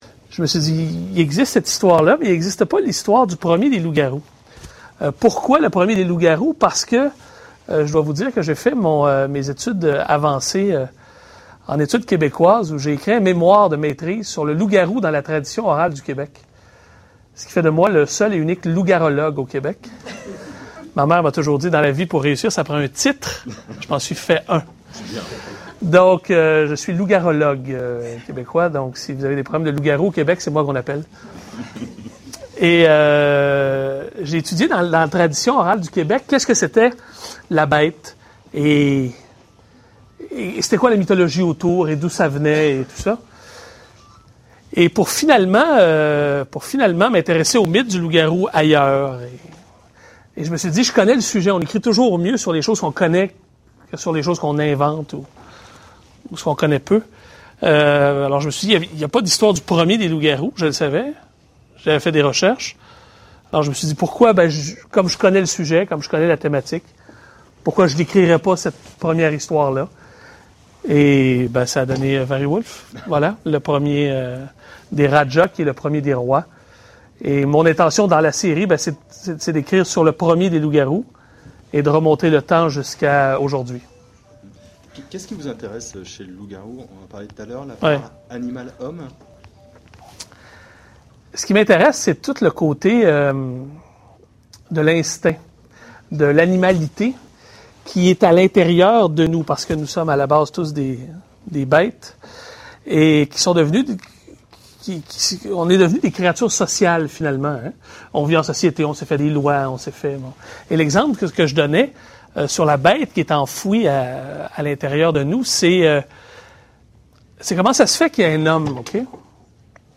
Conférence Bryan Perro
A l'occasion de la sortie de son roman Wariwulf , Bryan Perro était en France début septembre. Il a donné une petite conférence sur son livre à Paris dont voici l'enregistrement.